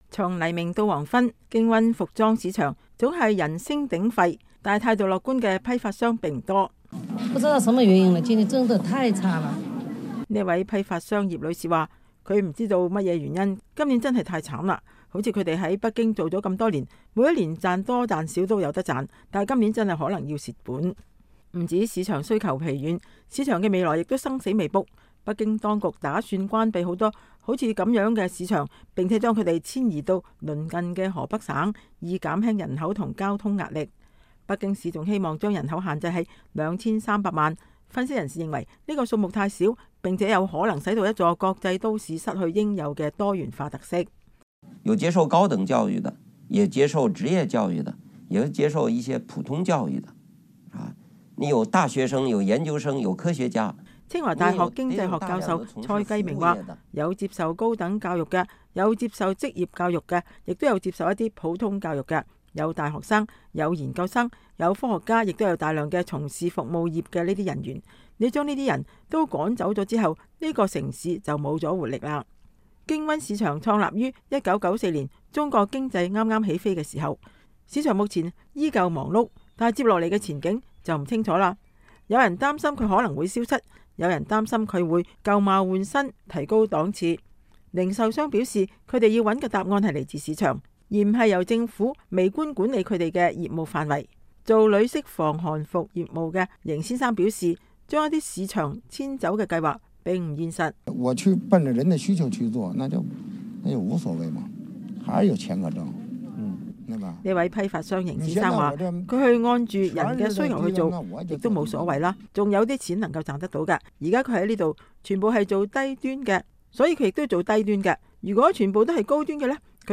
年終報道- 從批發市場觀測中國經濟走向